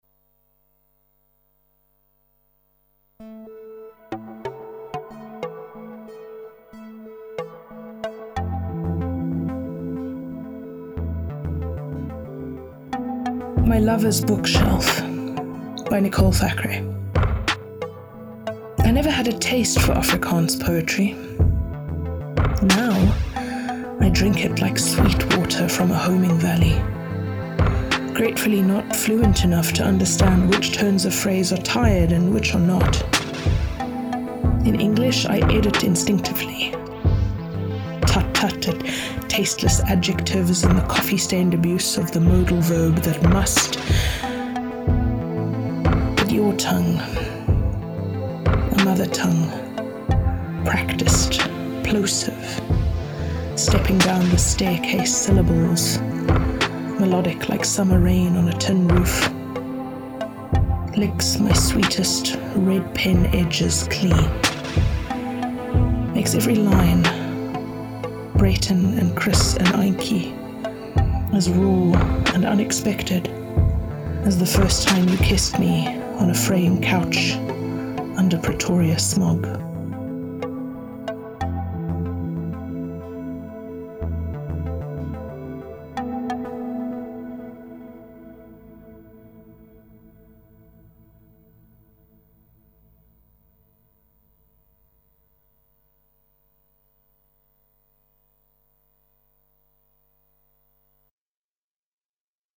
A collection of textured beats featuring artists’ contributions to Ons Klyntji 2023.